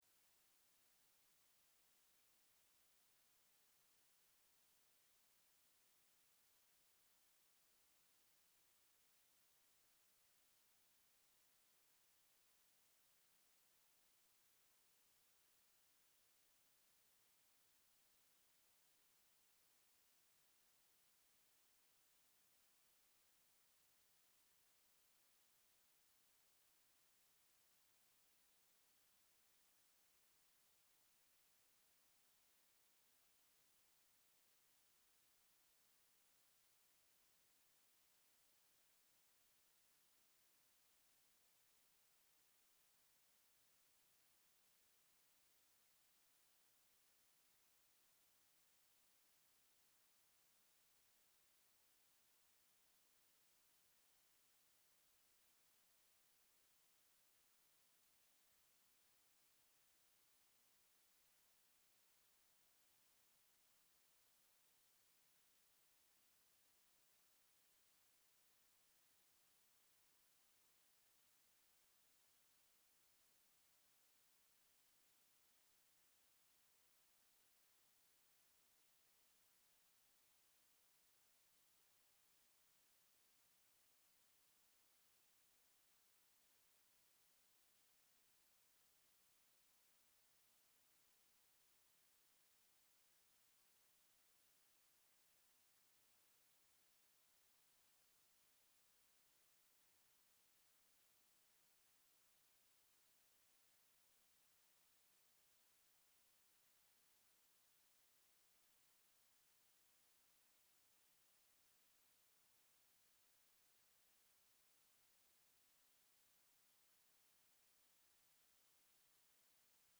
Sermon June 22, 2025